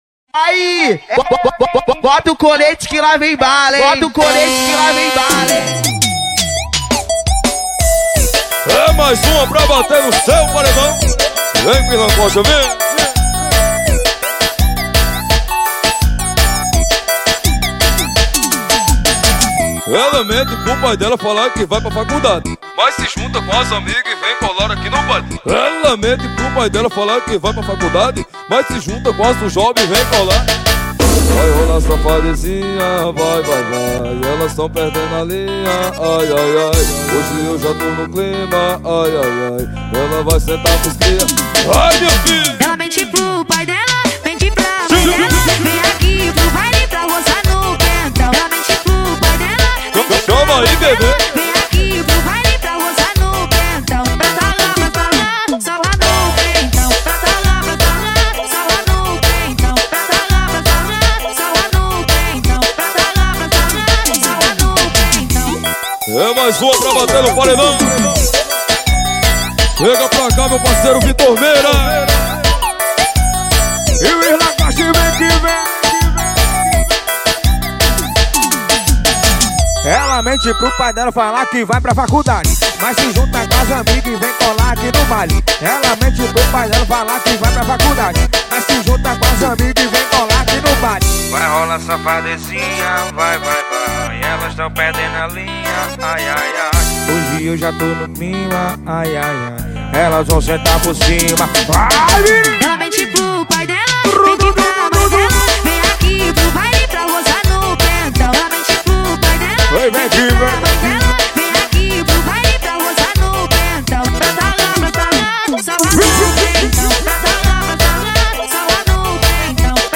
2024-11-25 11:29:32 Gênero: Forró Views